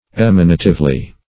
emanatively - definition of emanatively - synonyms, pronunciation, spelling from Free Dictionary Search Result for " emanatively" : The Collaborative International Dictionary of English v.0.48: Emanatively \Em"a*na*tive*ly\, adv. By an emanation.
emanatively.mp3